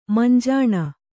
♪ manjāṇa